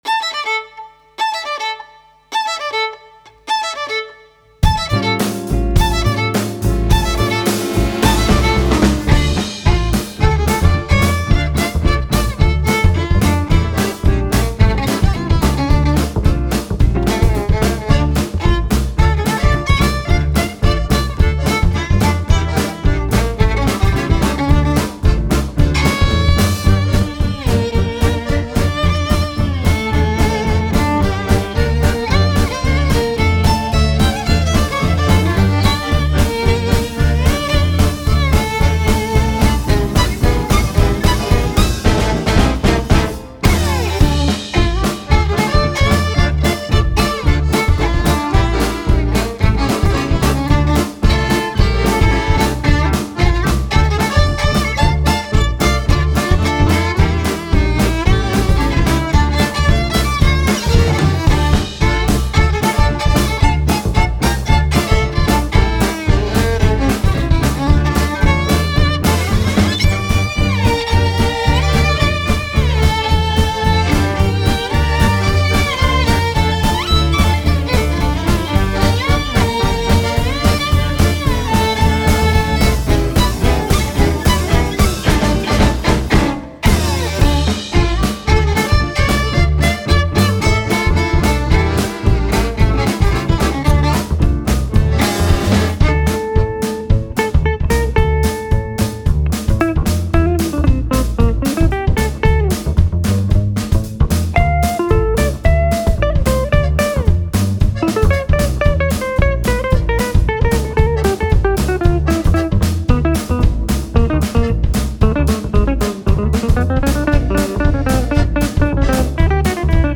Guitar/D'rbukka
Violin/Viola
Accordian
Double Bass
Drums